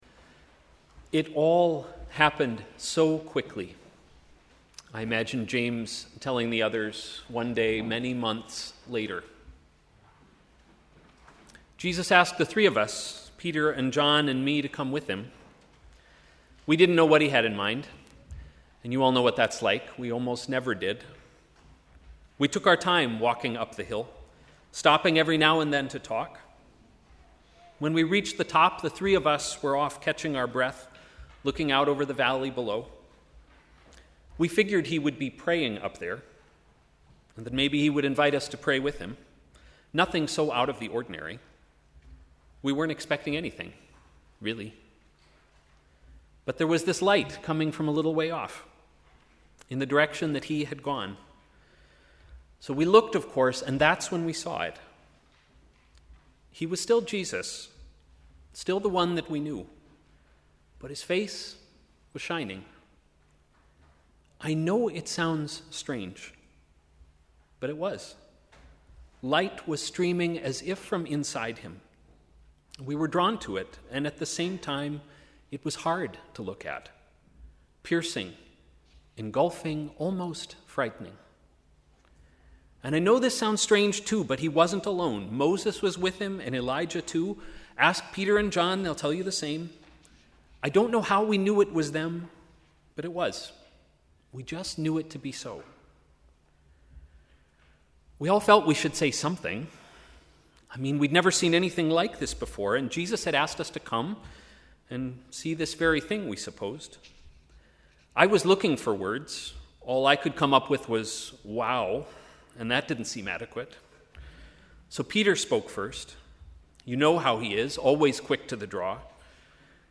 Sermon: ‘Brightness in the cloud’